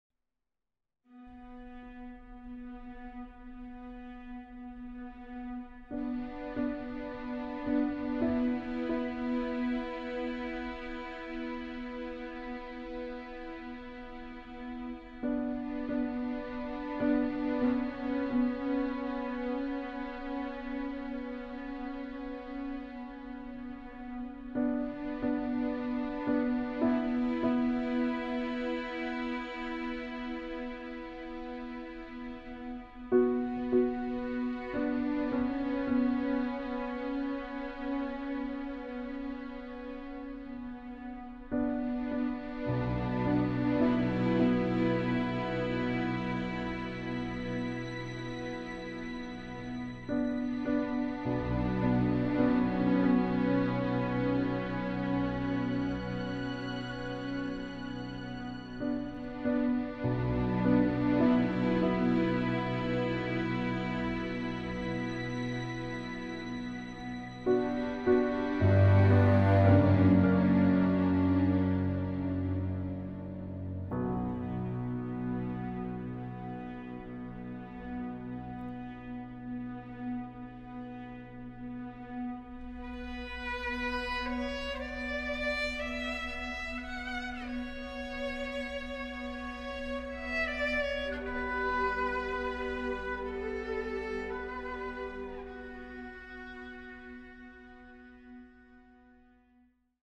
a dark and haunting soundtrack
an exciting and adreanaline-driving score